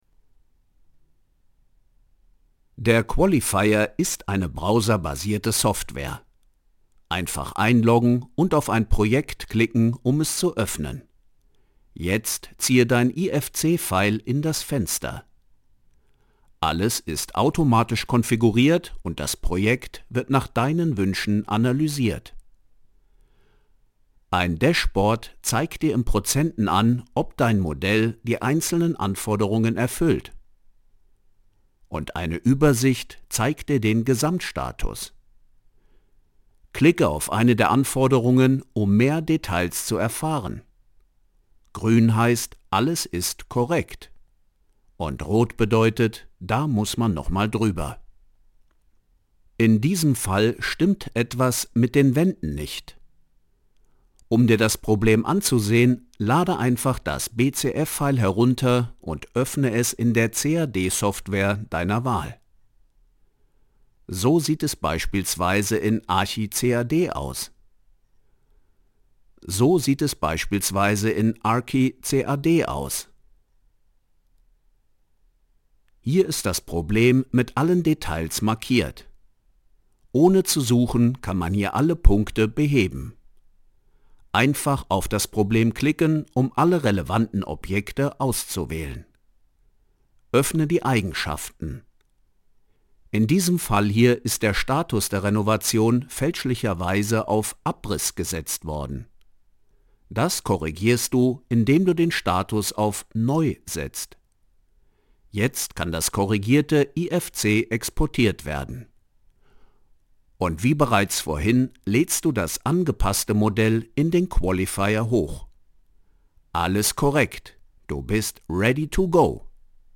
Professioneller deutscher Sprecher / voice-over.
Sprechprobe: eLearning (Muttersprache):
professional voice over, german speaking narrator (voice over, dubbing actor, video games, audio book, radio drama, docoumentary, advertising, poetry etc.).